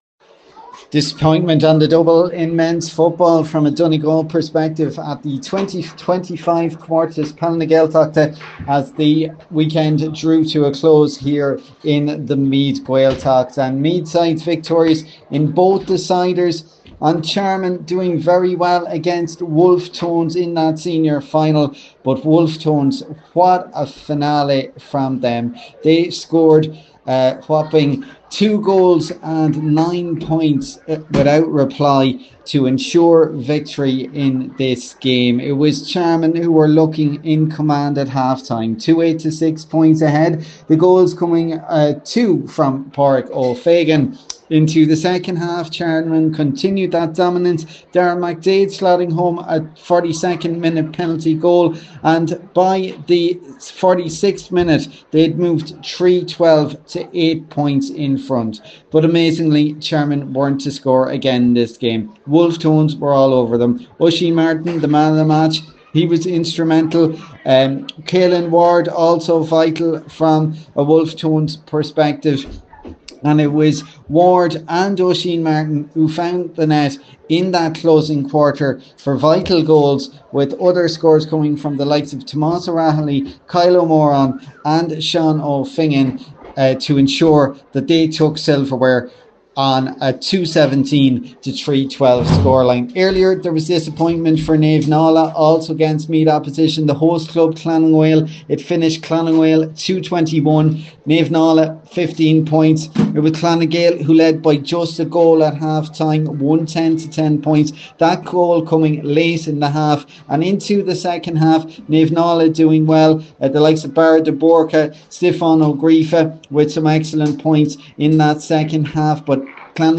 reports from Rathcairn…